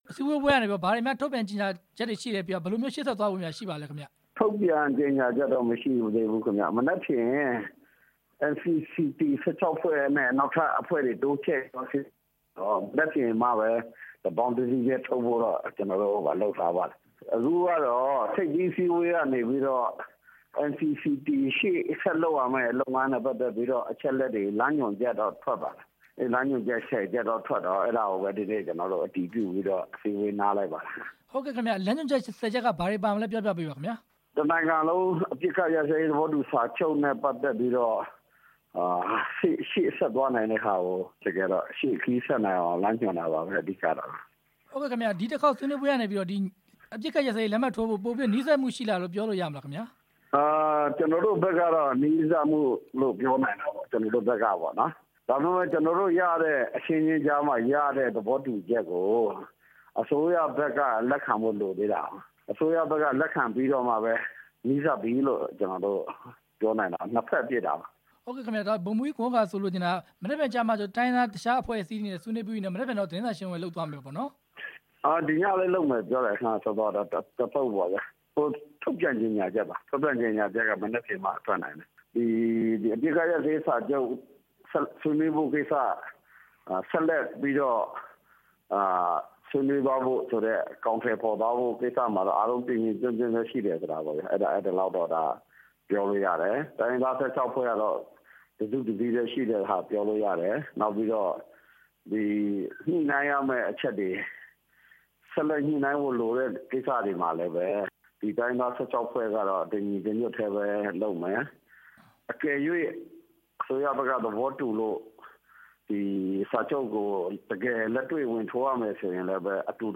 လိုင်ဇာမြို့က NCCT ဆွေးနွေးပွဲ ဆက်သွယ်မေးမြန်းချက်